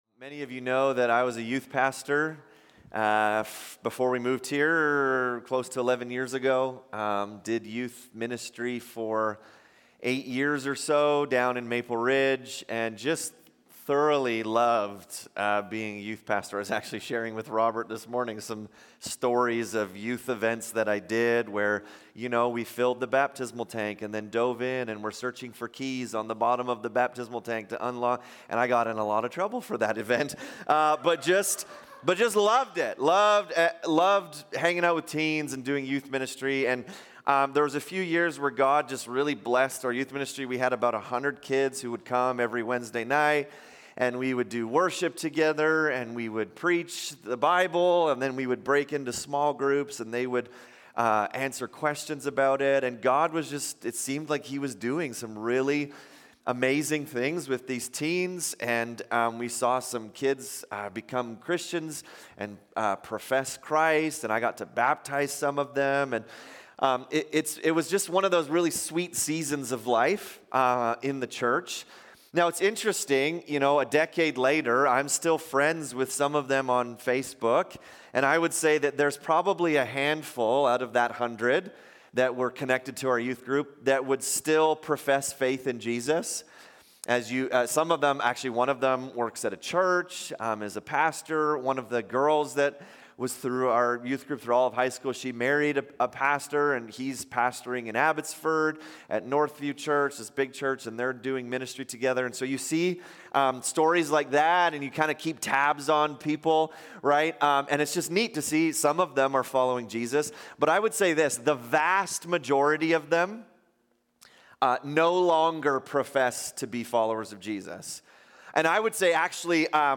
In this sermon we study the parable of the sower and the soils. A farmer sows seed in 4 different types of soil, yielding 4 different responses.